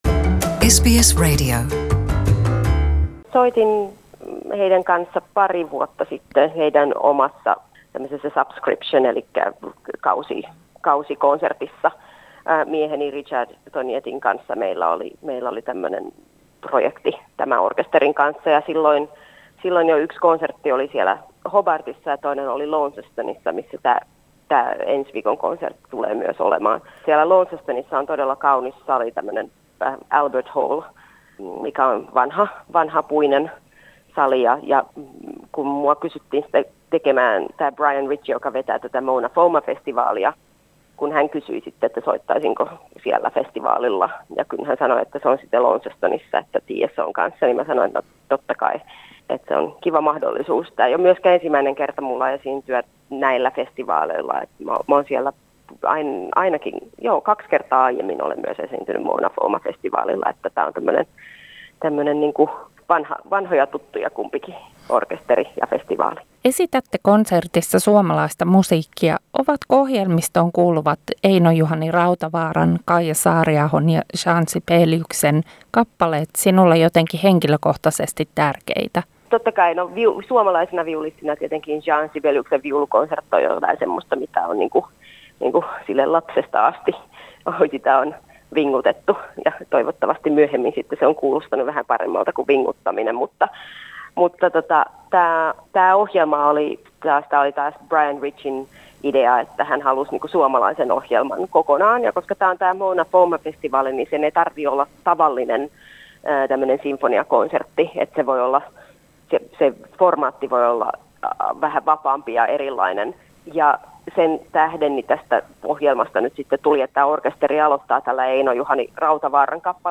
Haastateltavana viulisti Satu Vänskä